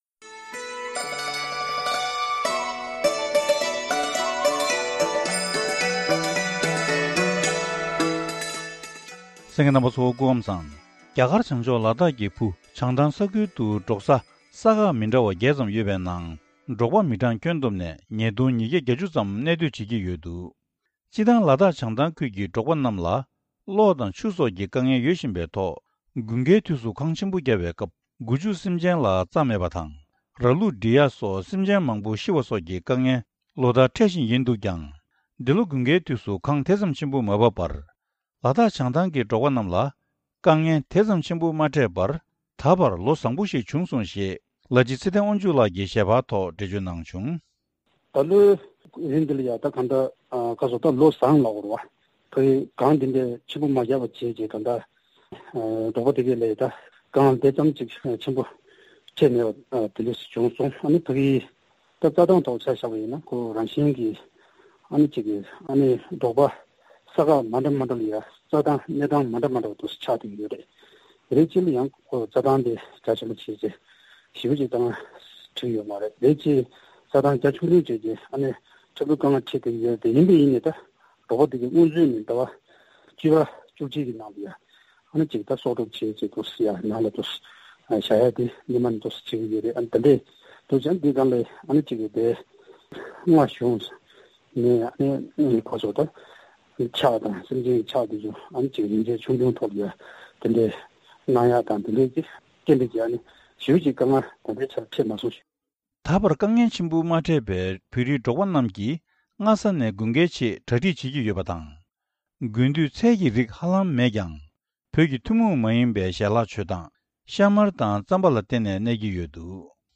བཀའ་འདྲི་ཞུས་ནས་ཕྱོགས་སྒྲིག་ཞུས་པ་ཞིག་གསན་རོགས་གནང་།།